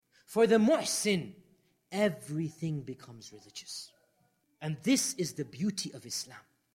audio_level_too_low.mp3